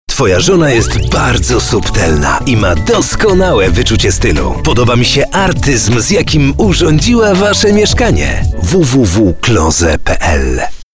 Professioneller polnischer Sprecher für TV/Rundfunk/Industrie.
Sprechprobe: Werbung (Muttersprache):